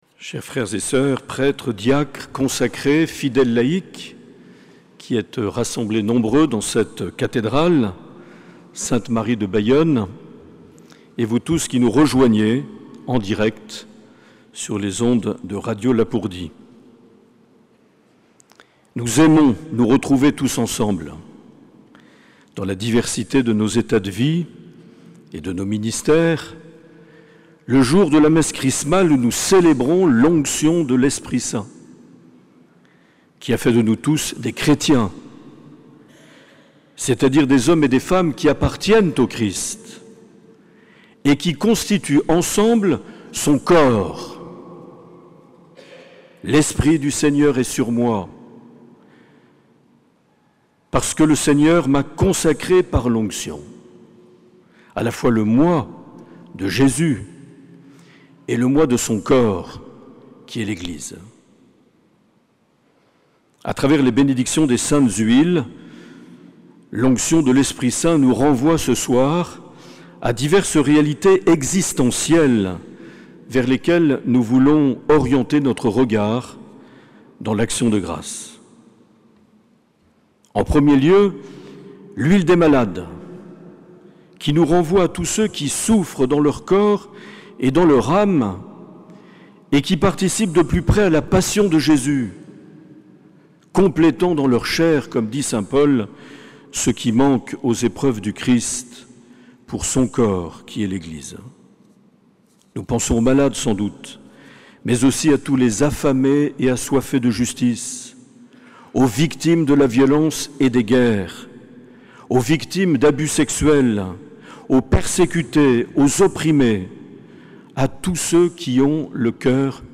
4 avril 2023 - Cathédrale de Bayonne - Messe Chrismale
Homélie de Mgr Marc Aillet.